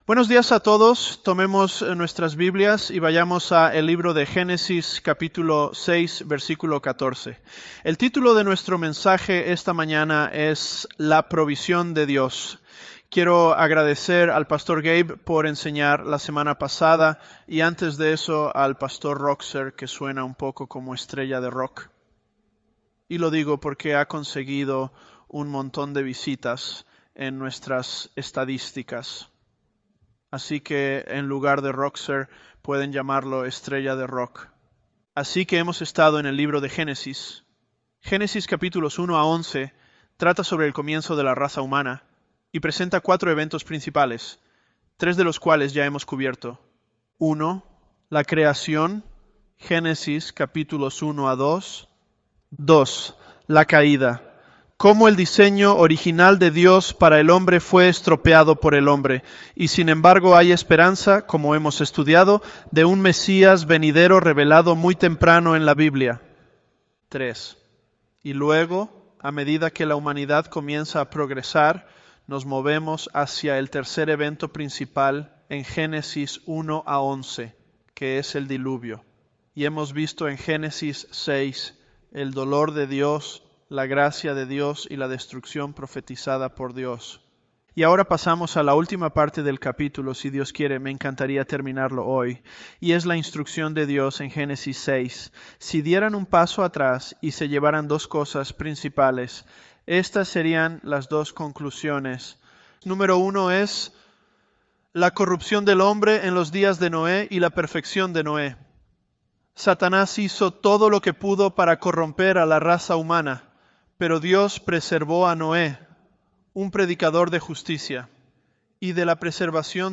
ElevenLabs_Genesis-Spanish029.mp3